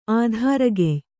♪ ādhāragey